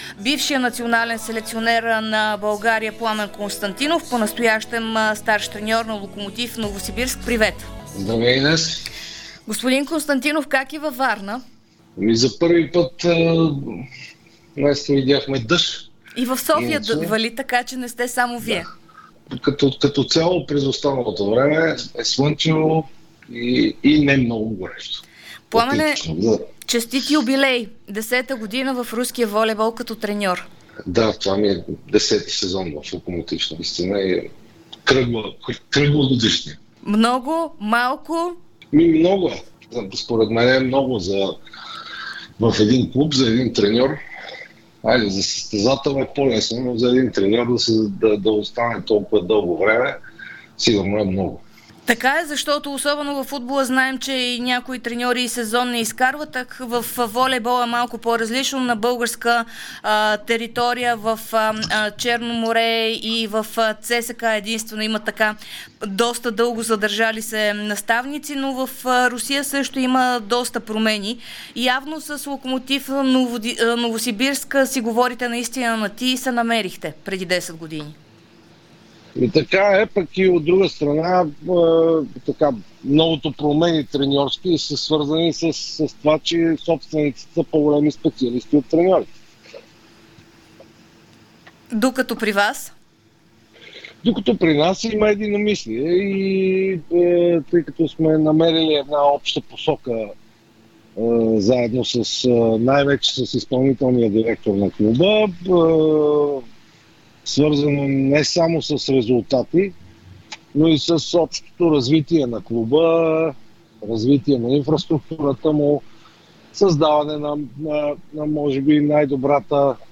Бившият национален селекционер на България по волейбол Пламен Константинов и настоящ наставник на руския Локомотив Новосибирск говори пред Дарик за новия разпределител на клуба му Симеон Николов, завръщането на Андрей Жеков в щаба му, националния отбор на България и юбилея си в Русия.